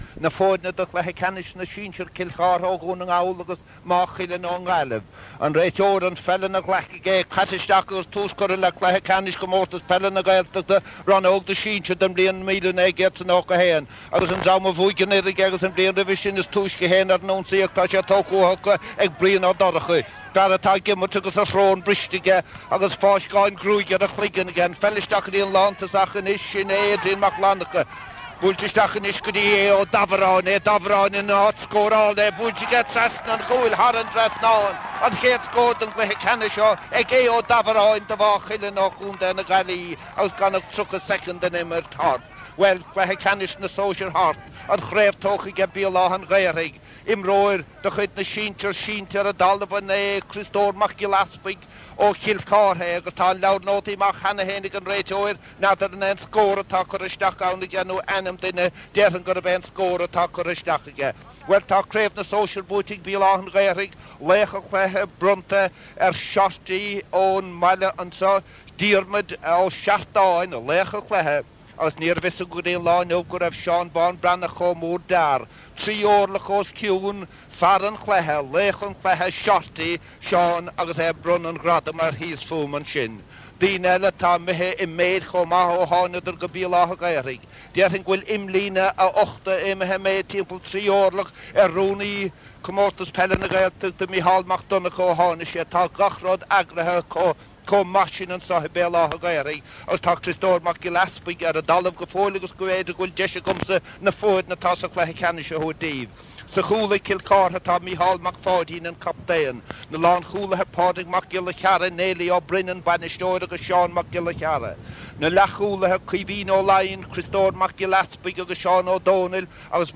Recordings of matches from the radio from the 1980s and 1990s